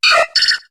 Cri de Togepi dans Pokémon HOME.